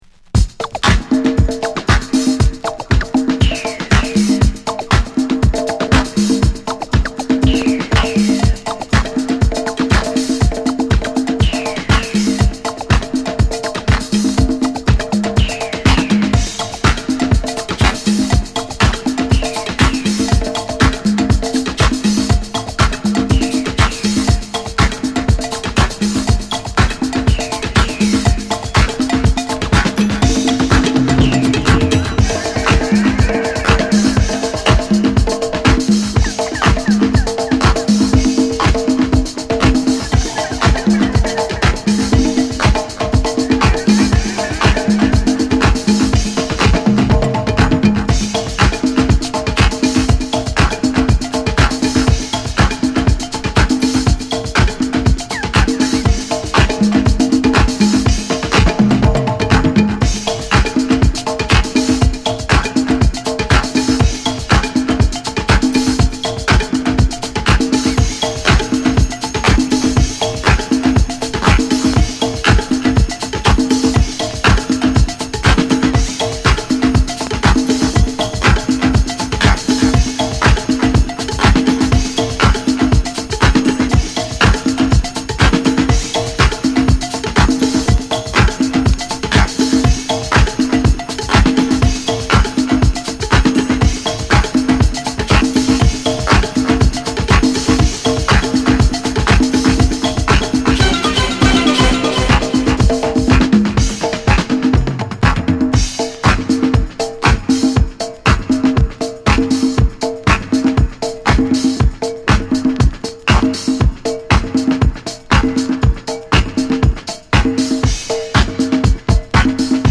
パーカッションを全面に配置したこれぞ、なディスコダブ決定版な